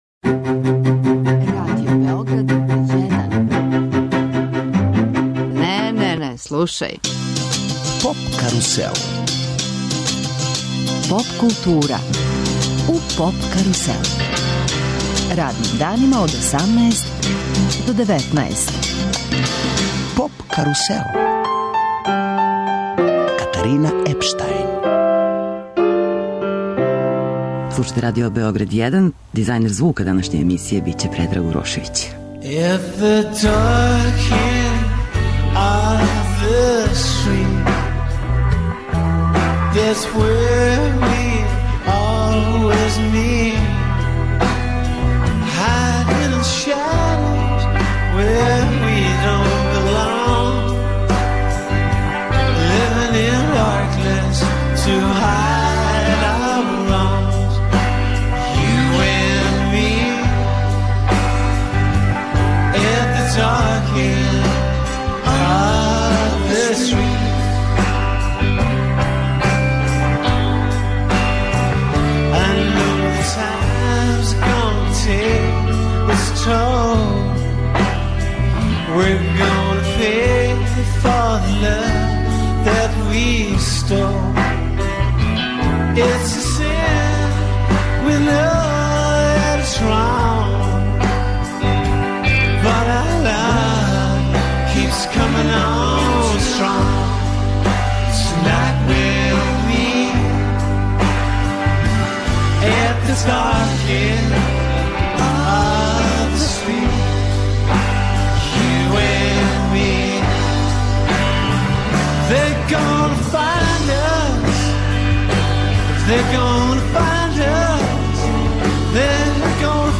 Нашим слушаоцима, свираће уживо из студија Првог програма.